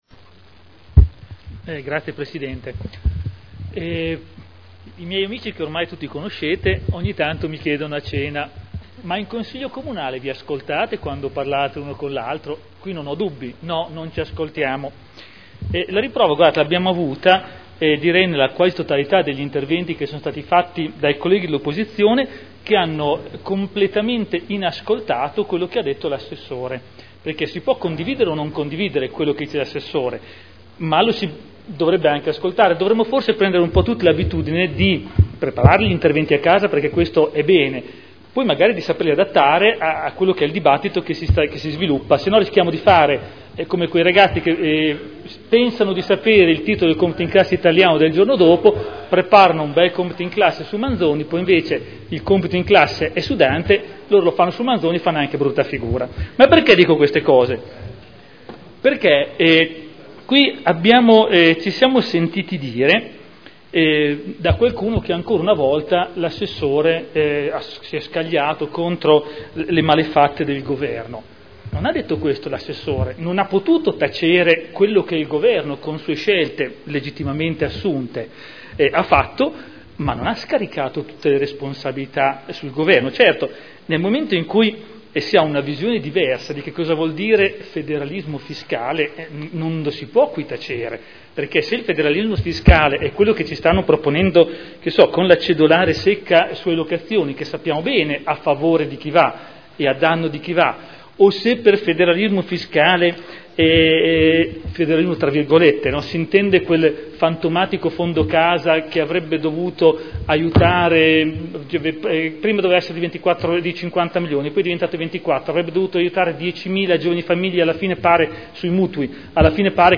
Gian Domenico Glorioso — Sito Audio Consiglio Comunale